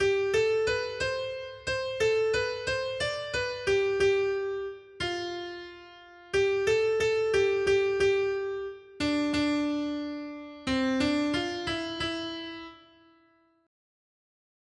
Noty Štítky, zpěvníky ol501.pdf responsoriální žalm Žaltář (Olejník) 501 Skrýt akordy R: Jeruzaléme, oslavuj Hospodina! 1.